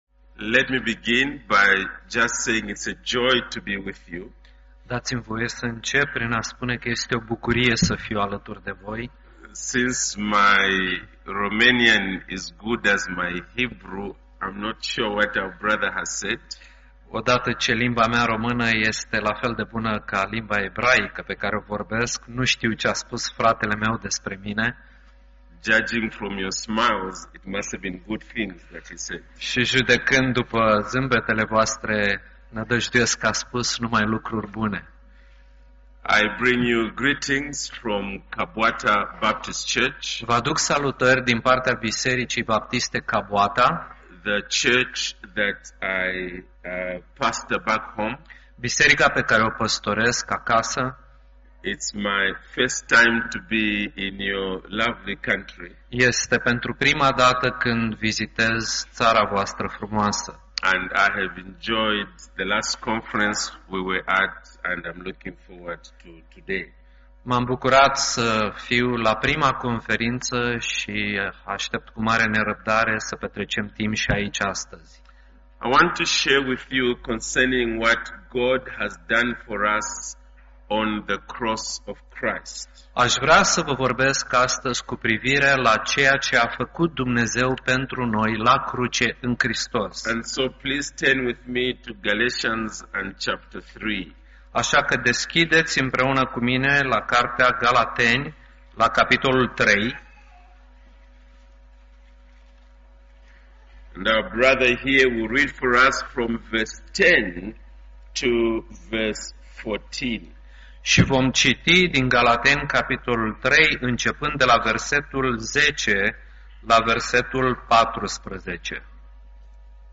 Conferința HeartCry 2010 (Brașov)